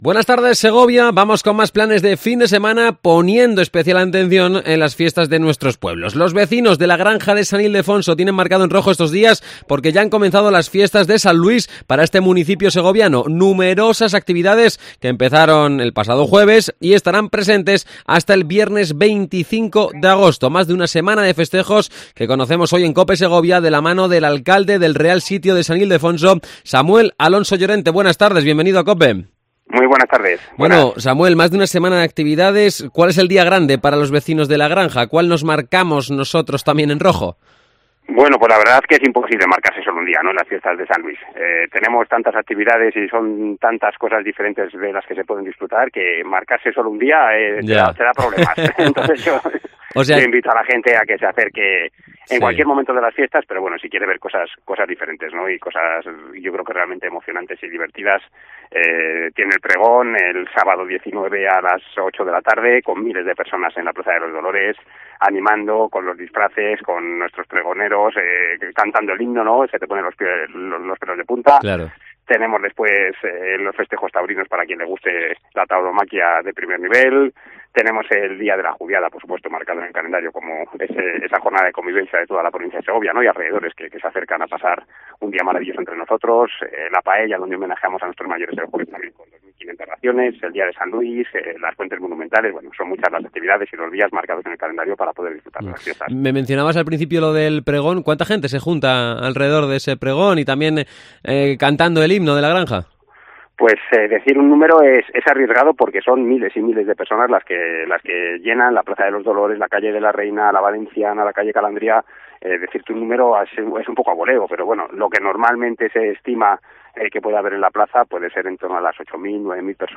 Entrevista a Samuel Alonso, Alcalde del Real Sitio de la Granja de San Ildefonso